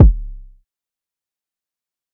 TC2 Kicks4.wav